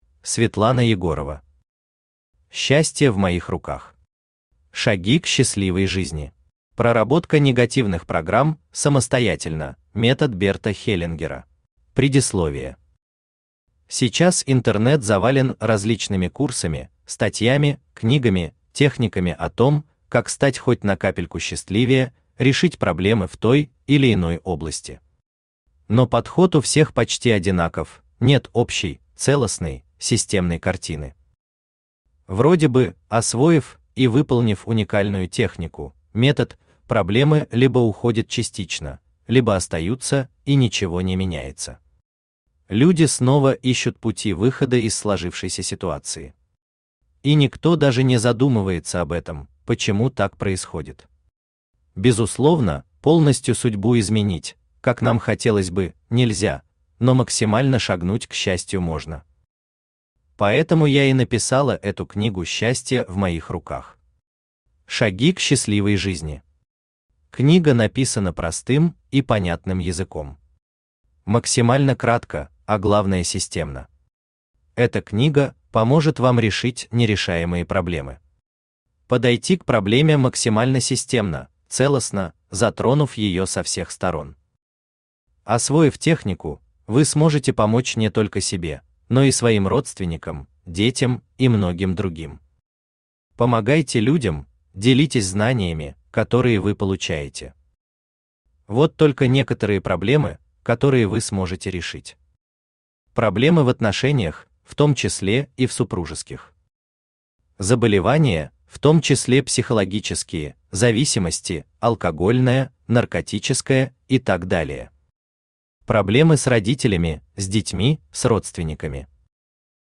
Читает аудиокнигу Авточтец ЛитРес